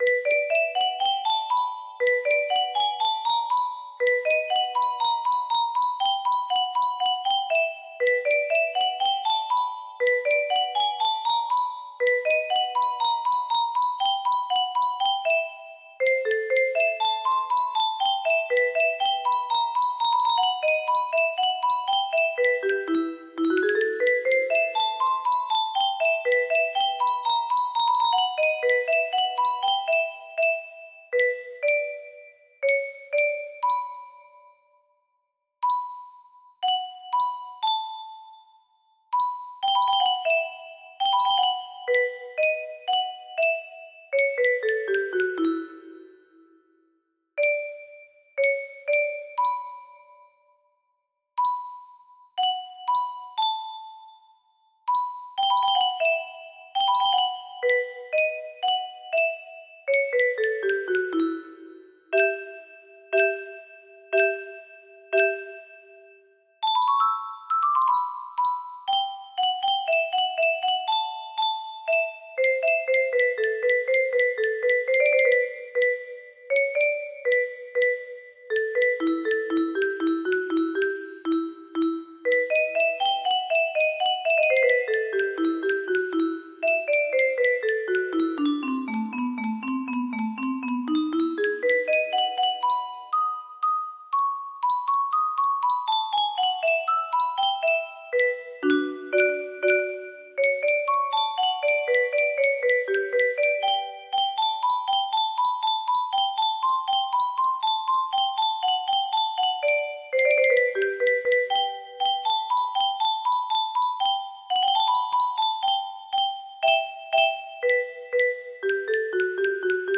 Bluegrass In The Backwoods music box melody